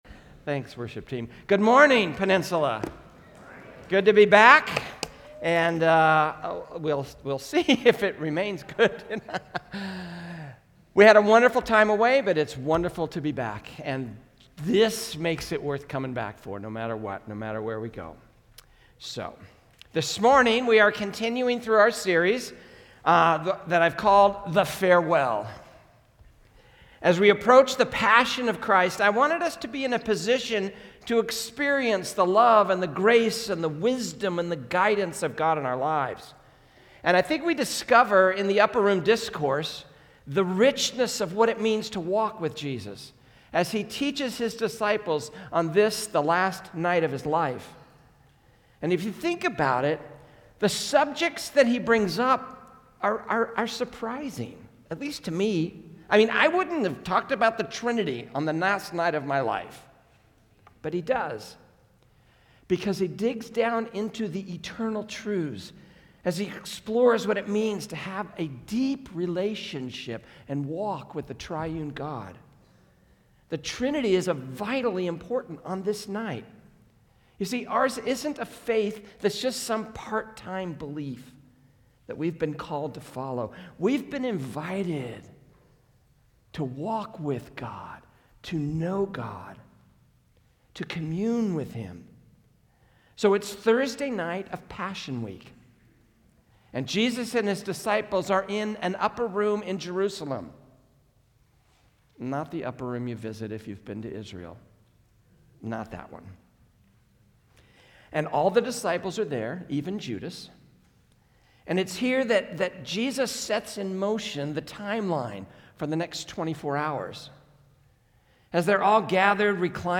A message from the series "The Farewell." The resurrection of Jesus Christ profoundly changed the lives of those who followed Him and it can transform our lives as well.